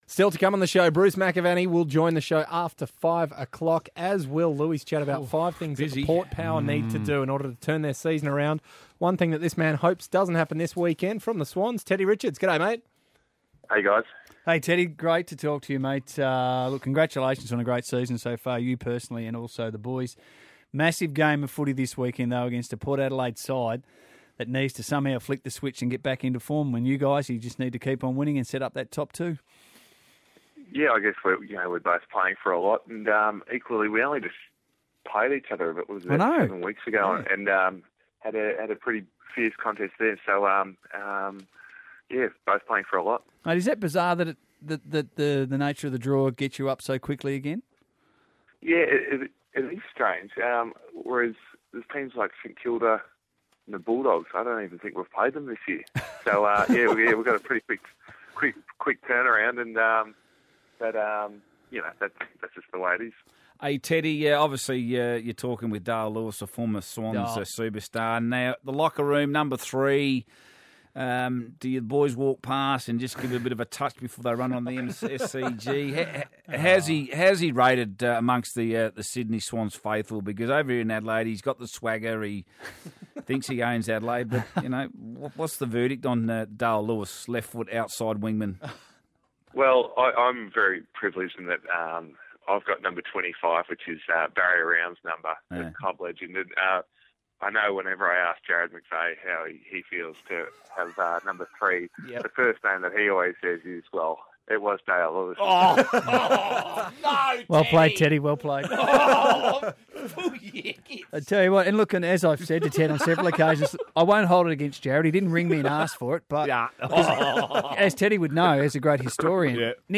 Sydney Swans defender Ted Richards appeared on Triple M Adelaide on Thursday August 7, 2014